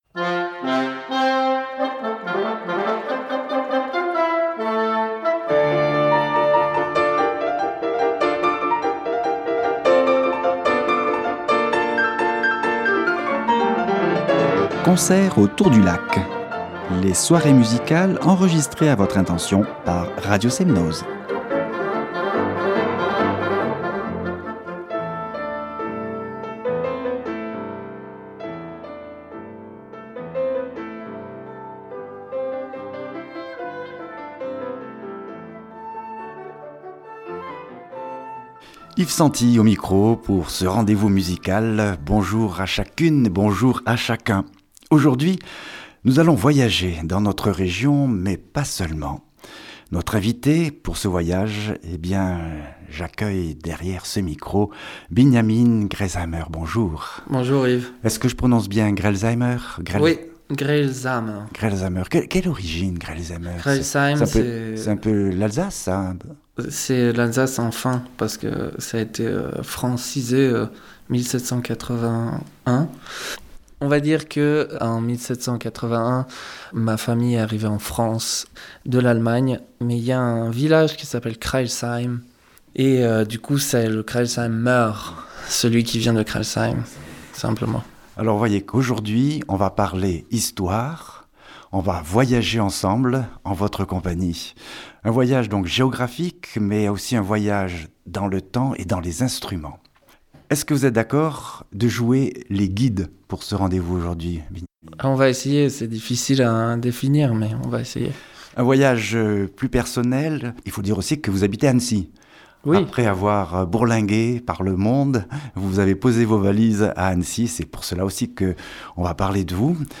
Interview pour Radio Semnoz
Interview dans l’émission « Concert au bord du lac » de Radio Semnoz, Annecy, pour la sortie de l’album « La Notte di Michelangelo”